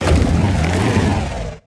avalugg_ambient.ogg